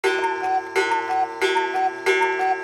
02 Alarm.aac